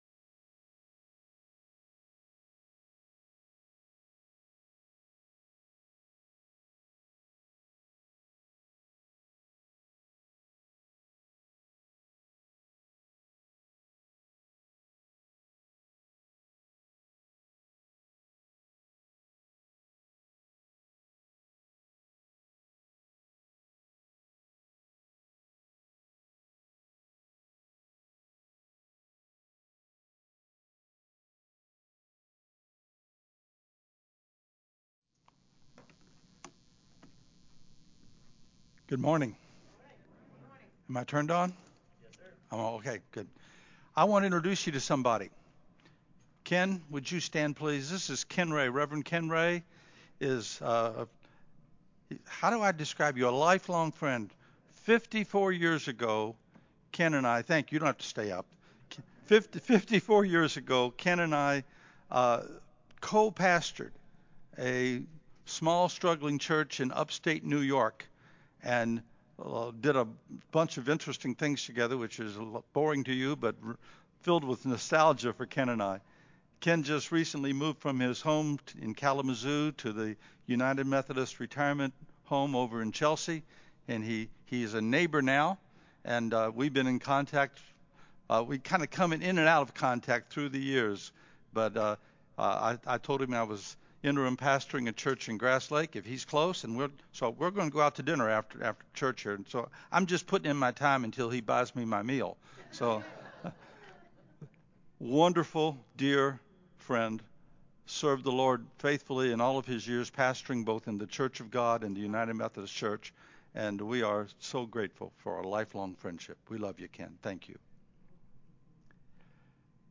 “The Bones of Marcellus” Sermon
0:00 Announcements 0:36 Sermon Opening 14:30 Outro